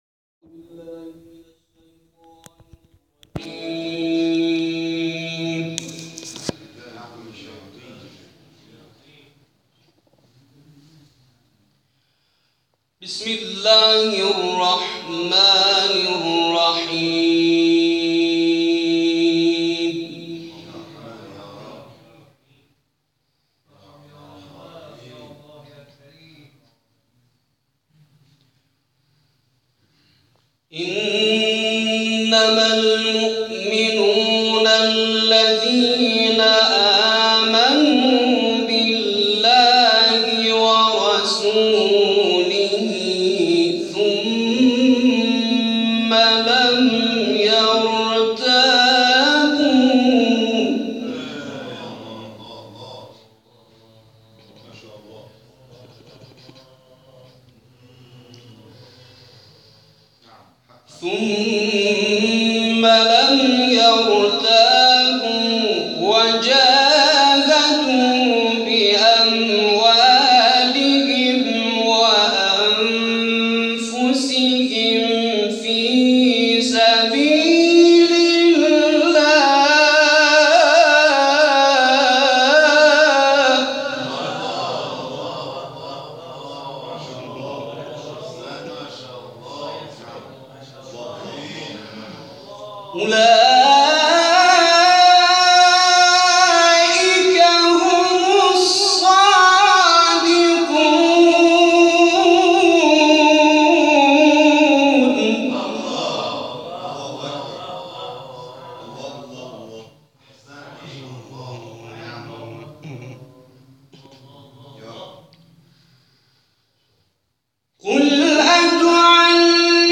در ادامه تلاوت‌های این مراسم ارائه می‌شود.
تلاوت